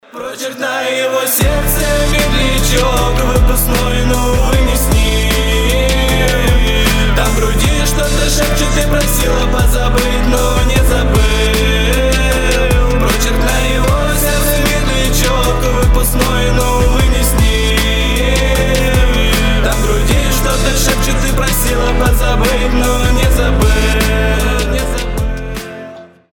поп , медленные